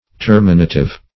Terminative \Ter"mi*na*tive\, a.